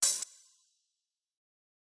Closed Hats
Metro Hats [Old].wav